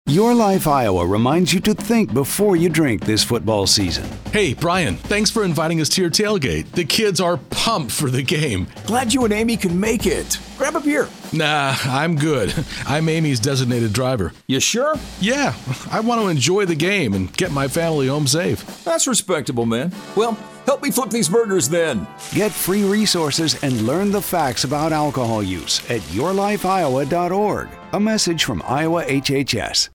Radio spot :30 Radio | Think Before You Drink - Football This campaign provides education and prevention resources to encourage low-risk alcohol consumption by older adults.